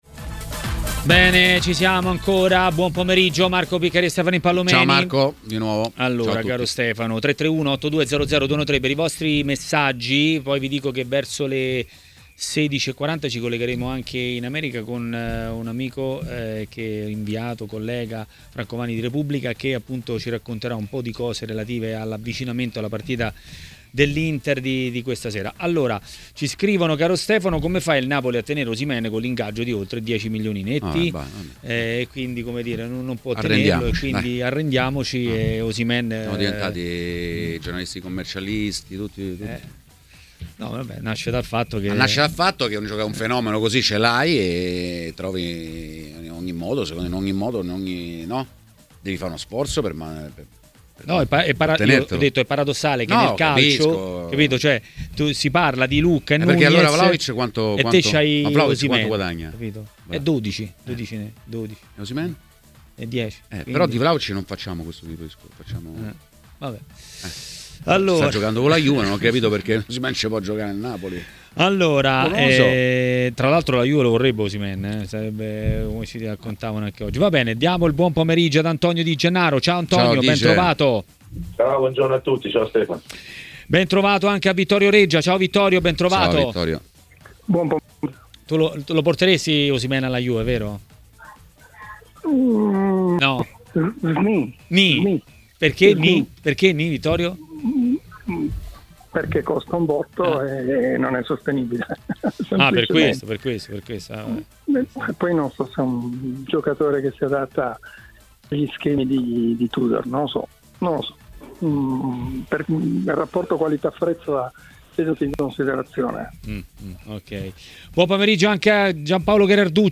Ospite di Maracanà, nel pomeriggio di TMW Radio, è stato l'ex calciatore e commentatore tv Antonio Di Gennaro.